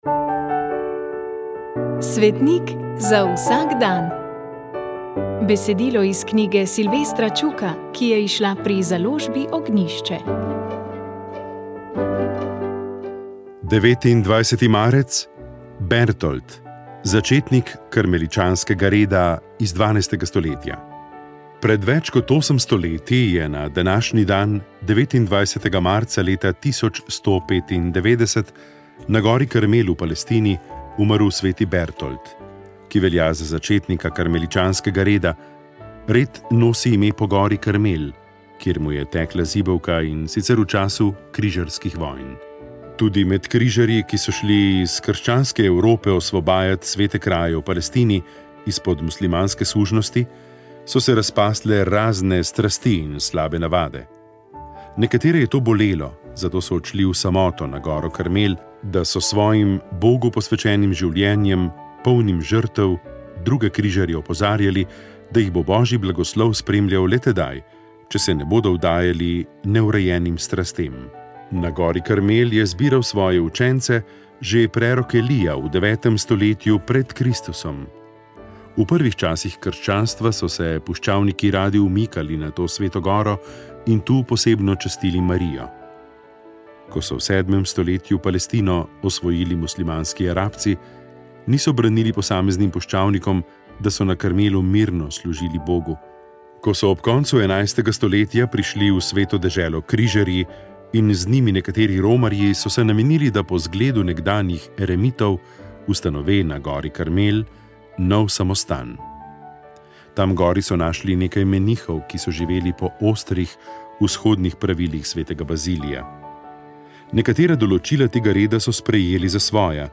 Pred mikrofoni sta se spet srečala oba gosta letošnjega cikla Dialog z ateizmom